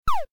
pew_.mp3